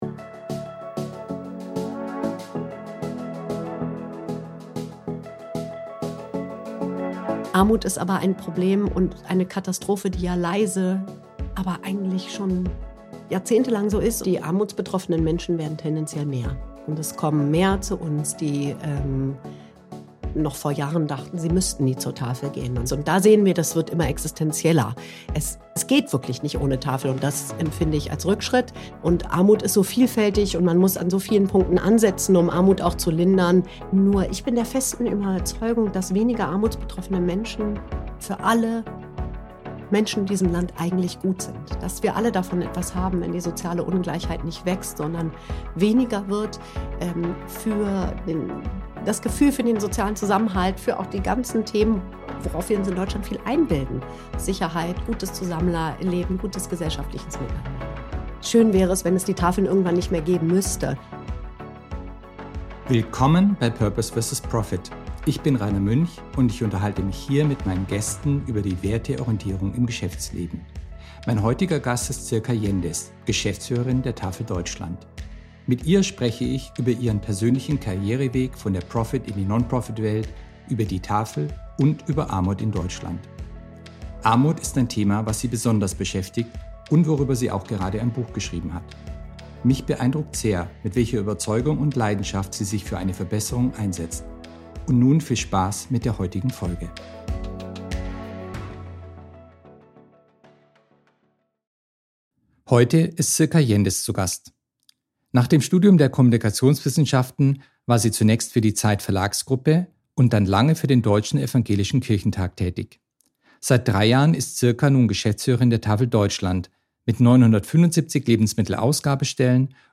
Das Gespräch wurde aufgezeichnet am 29. Januar 2025.